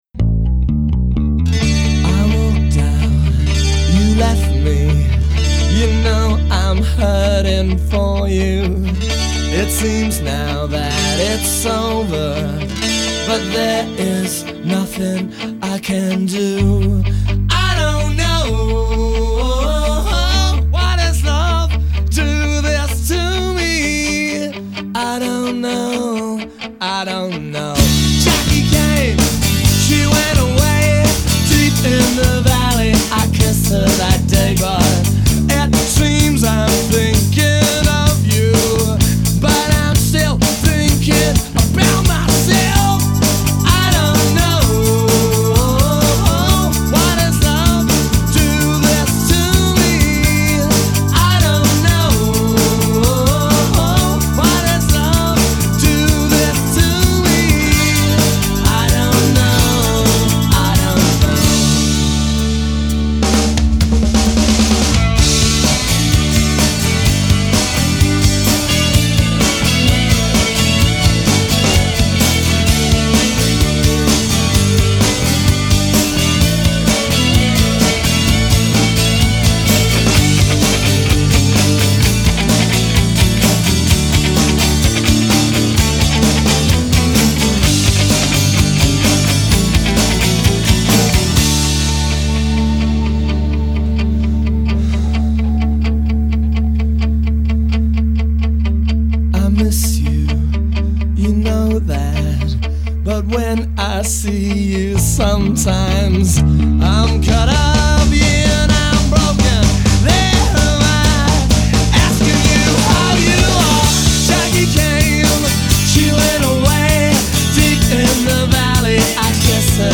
Genre: Hip Hop, Jazz, Rock, Funk